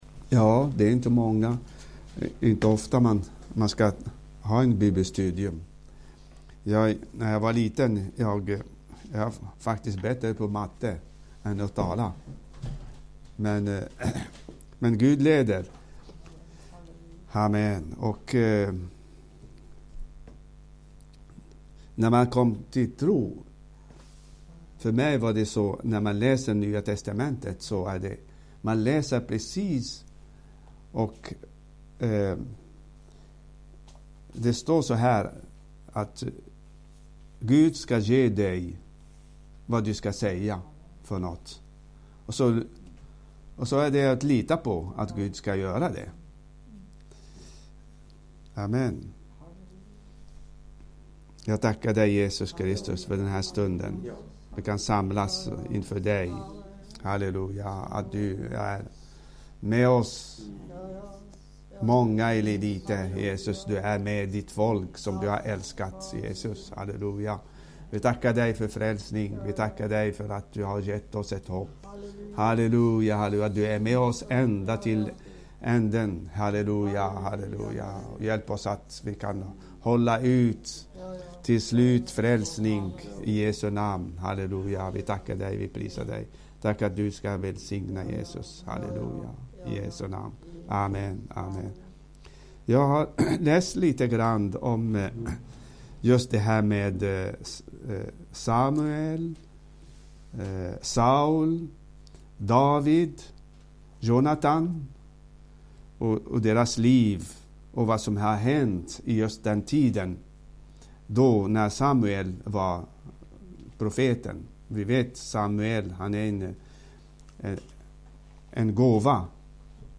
Bibelstudium
inspelat i Skälby 19 mars 2025.